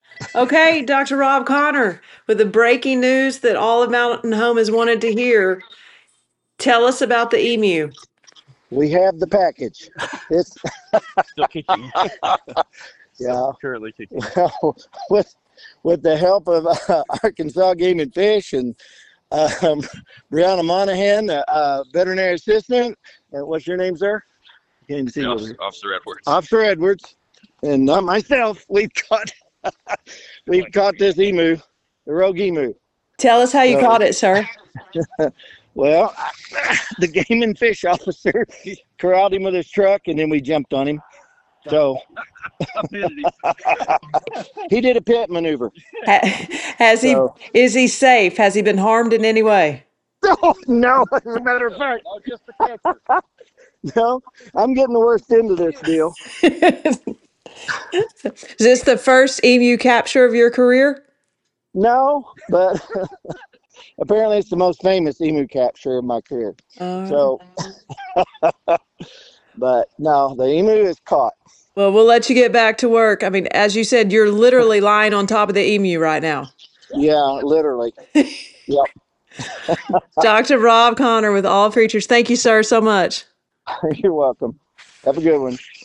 Emu captured